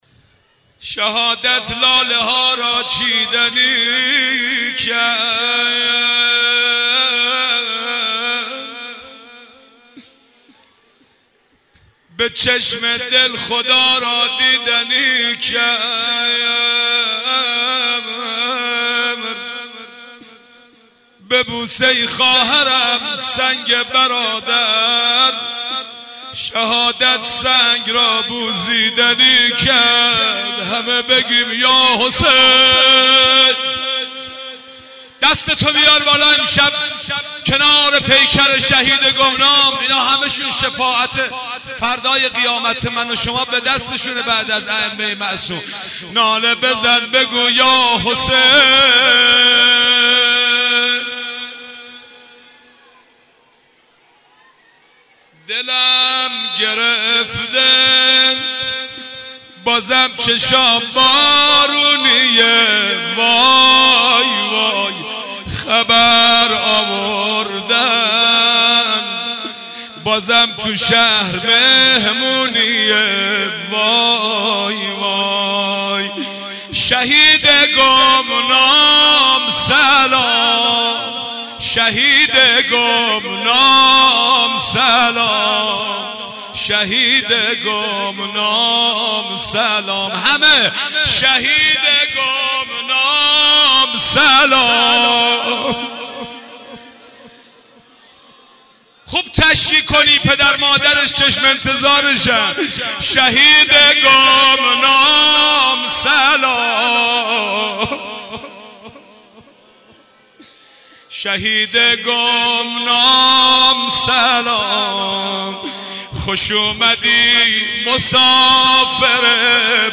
مراسم وداع و تشییع با شهید گمنام در دو تاریخ سوم محرم و بیست و هشتم صفر در مسجد برگزار شد.